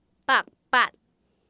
臺灣客語拼音學習網-進階學習課程-饒平腔-第五課